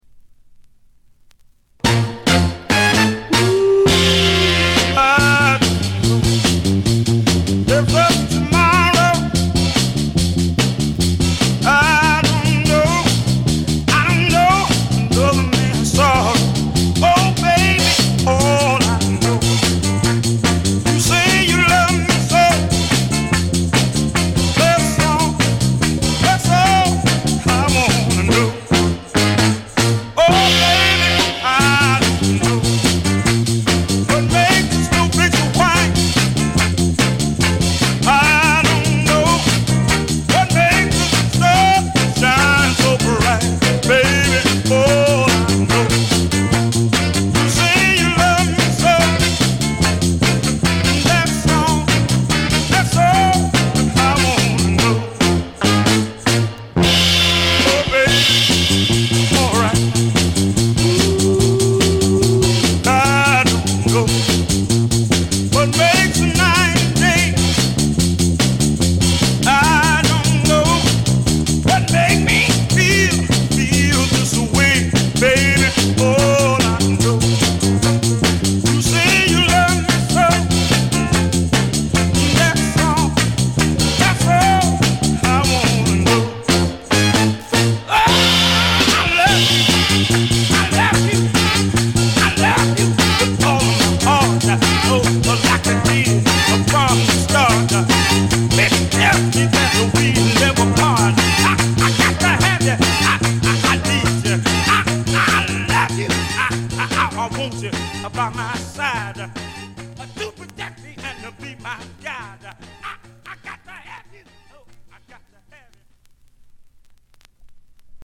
モノラル・プレス。
試聴曲は現品からの取り込み音源です。